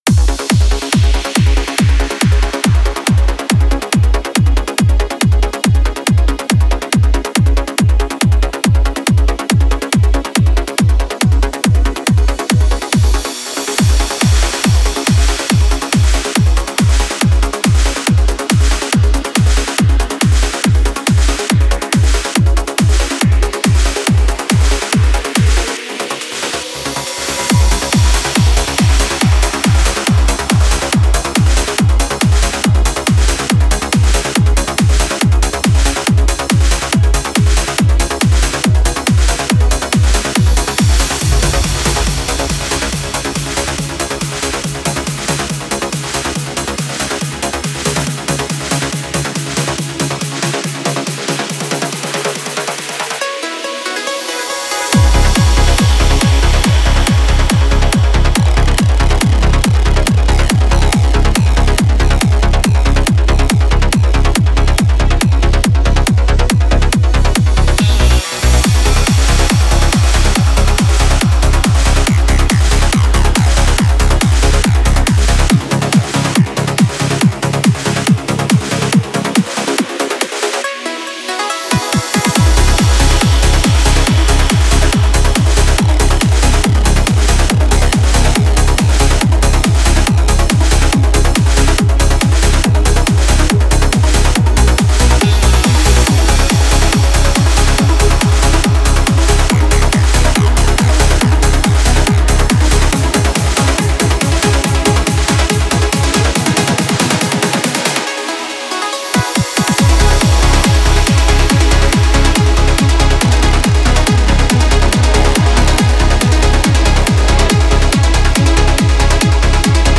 Trance retro